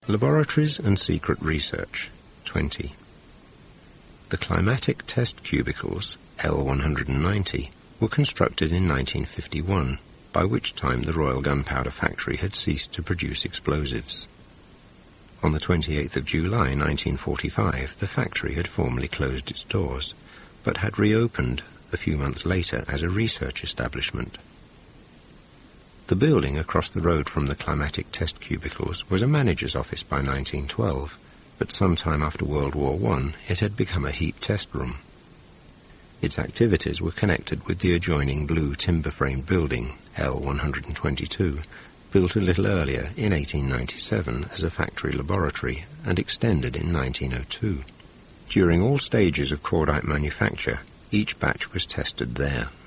WAOH - Waltham Abbey Oral History
WARGM Audio Tour 2001
Format: Cassette Tape